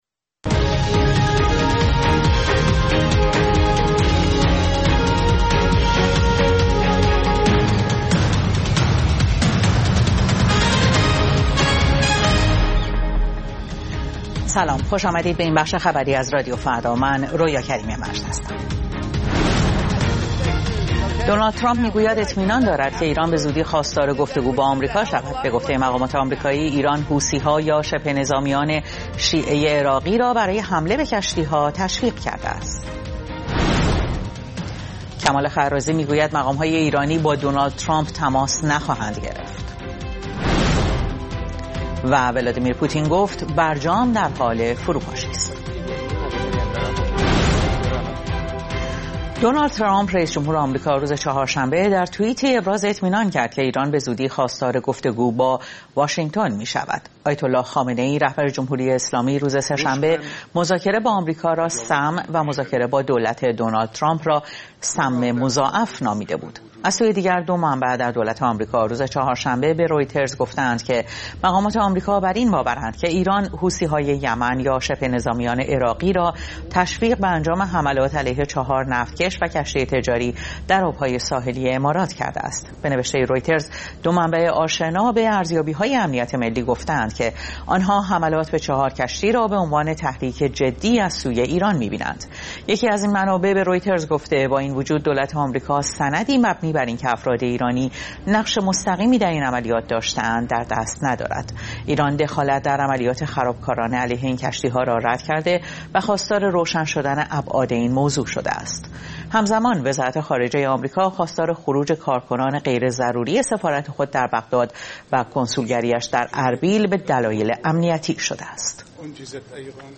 اخبار رادیو فردا، ساعت ۹:۰۰